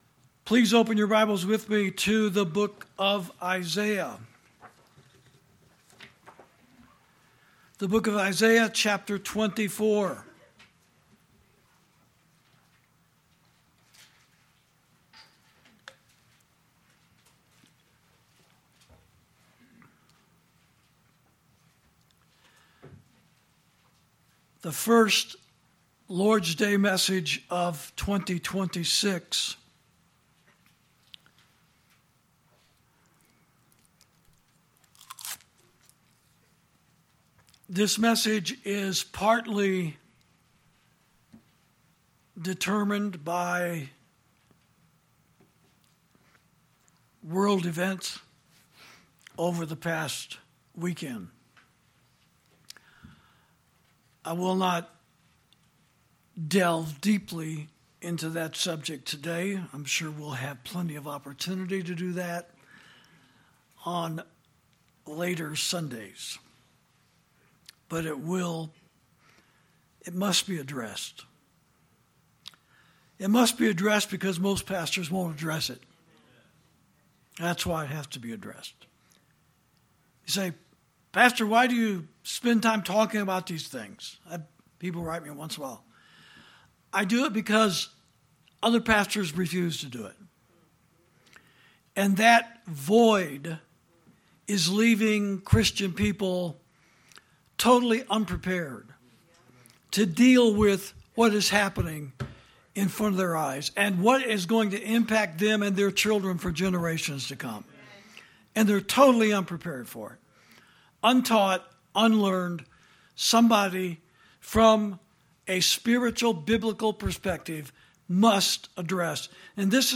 Sermons > 2026 Began With The Sound Of War From Our President And The Sound Of Silence From Our Pulpits